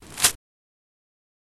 Foliage | Sneak On The Lot